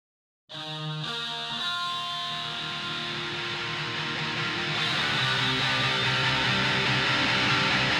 44_1Khz_stereo.ogg